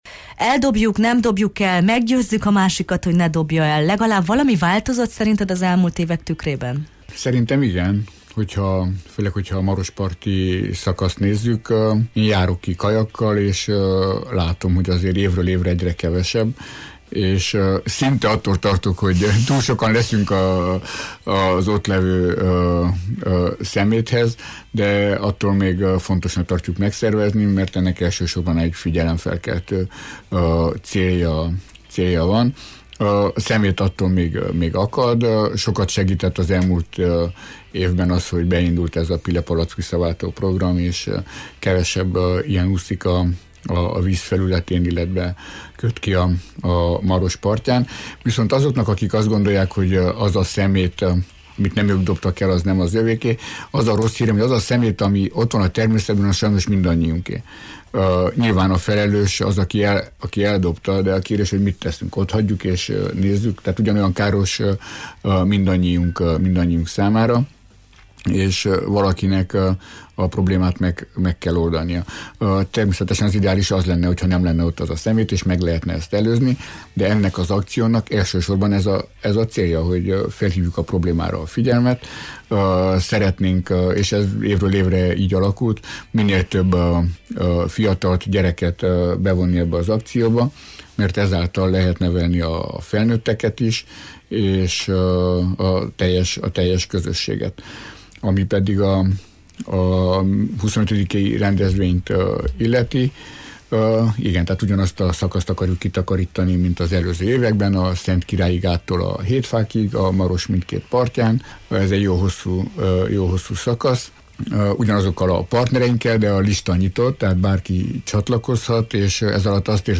A Jó reggelt, Erdély!-ben hallgatóinkat is meghívtuk az eseményre: